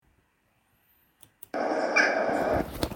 Audioaufnahmen aus dem Schutzgebiet
Dohle